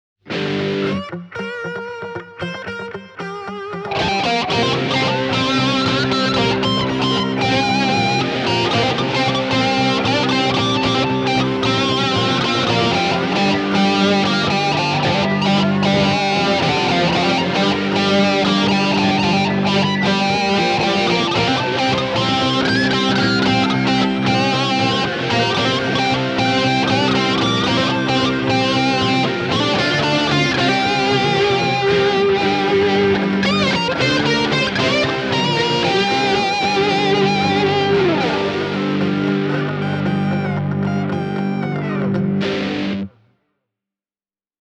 Pearly Gates –mikrofoneissa on huomattavan paljon enemmän tehoa kuin tavallisissa PAF (-kopioissa), ja myös keskialueesta löytyy rutkasti vääntöä.
Billy Gibbons -tyylinen huiluäänikikkailukin on helppoa, kun käytössä on oikeanlainen kitara soittotyyliä tukevilla mikrofoneilla: